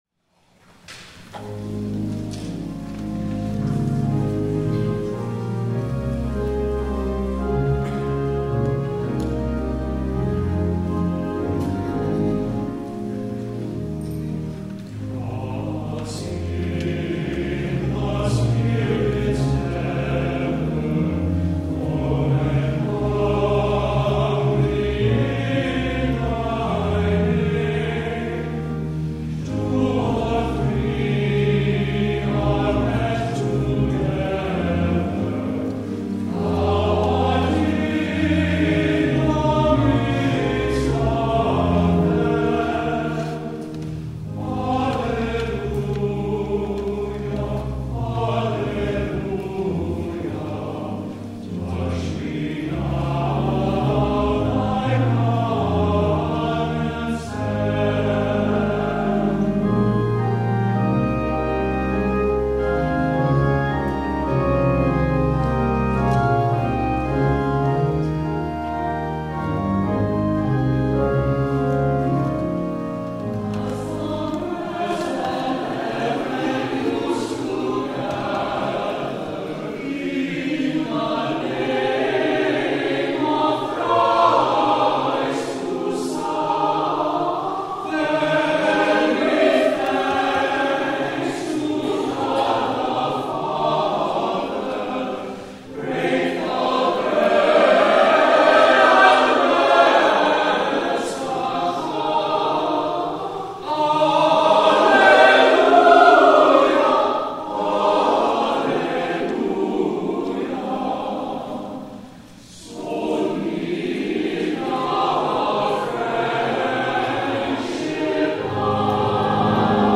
DAY OF PENTECOST
THE ANTHEM